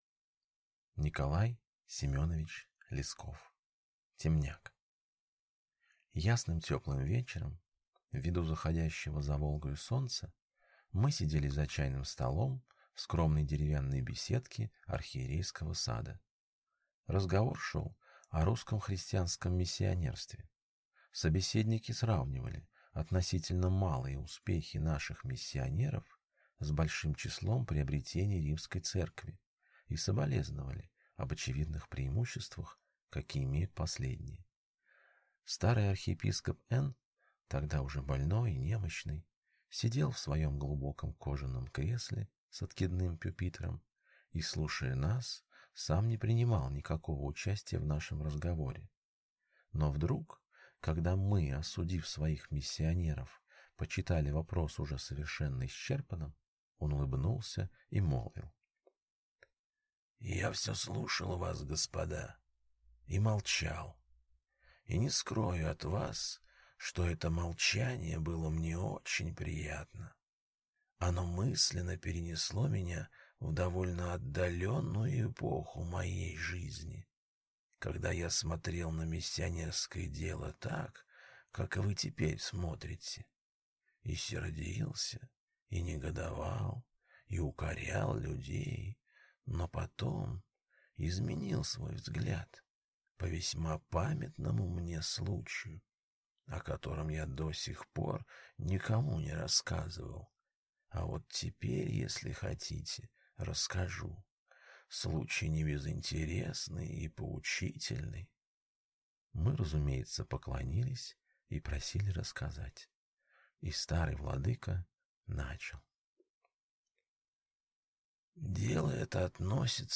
Aудиокнига Темняк